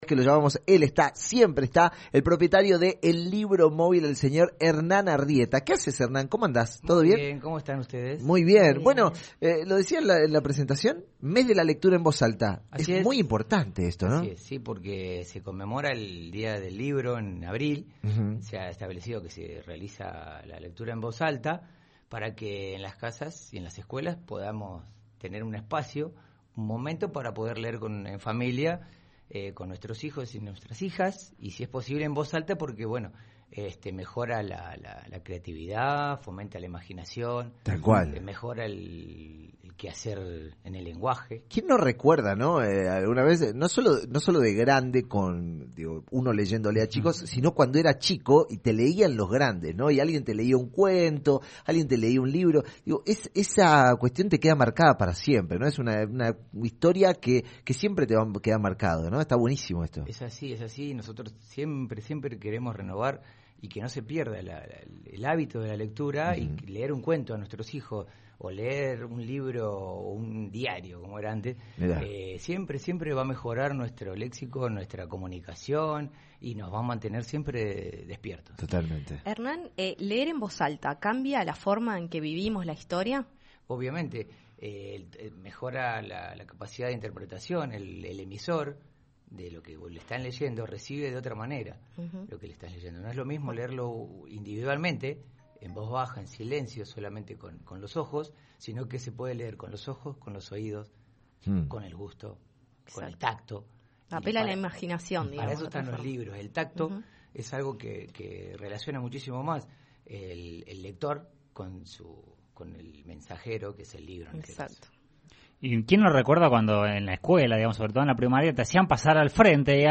en su paso por los estudios de LV18